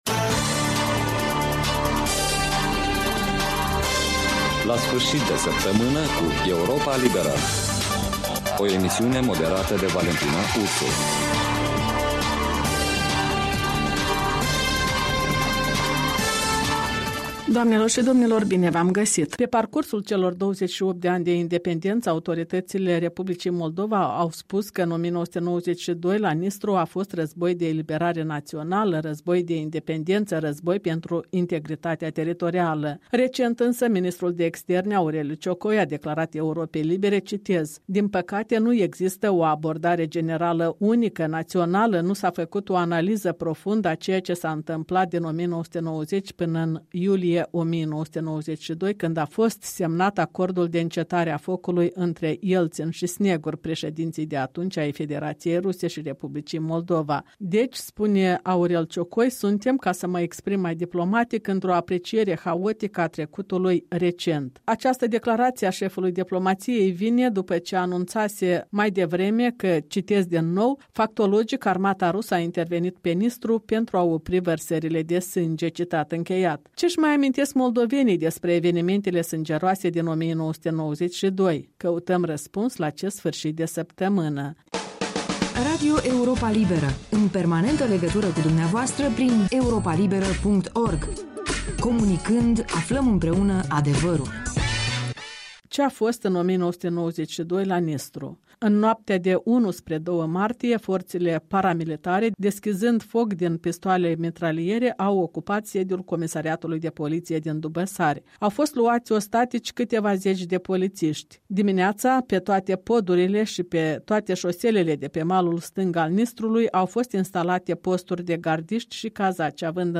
în dialog cu locuitori din Cimișlia